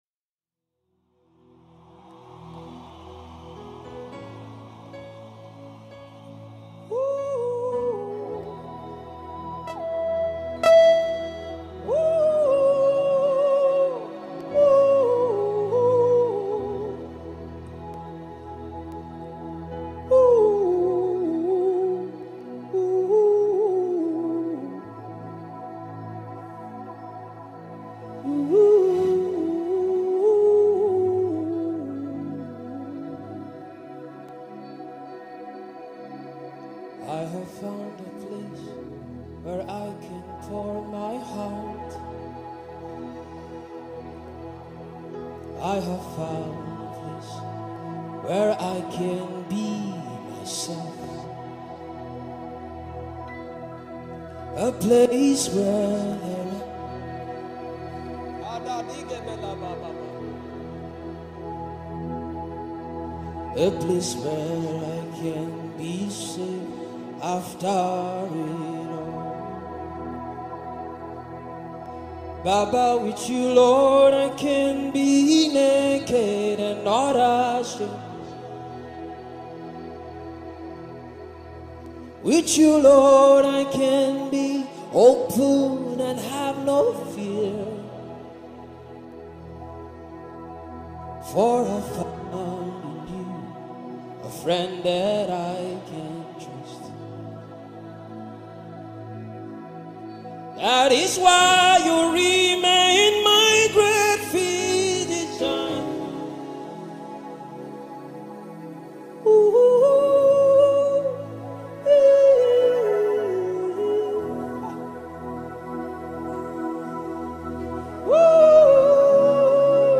soul-lifting gospel song
soul-stirring gospel anthem